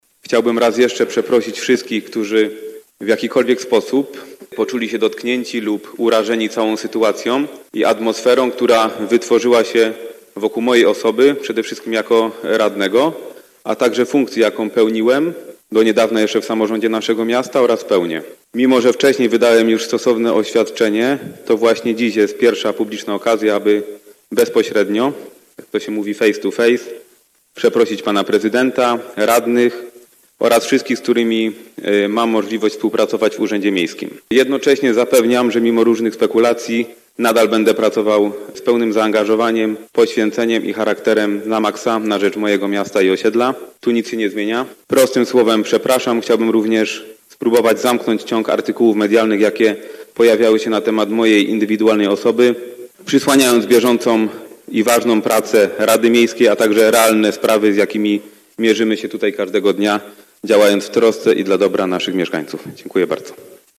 – Mimo, że wcześniej wydałem oświadczenie, dzisiaj jest pierwsza publiczna okazja, aby bezpośrednio przeprosić pana prezydenta, radnych oraz wszystkich, z którymi mam możliwość współpracować w Urzędzie Miejskim w Bielsku-Białej – mówił radny Maksymilian Pryga, podczas dzisiejszej sesji Rady Miejskiej.
Cała wypowiedź radnego: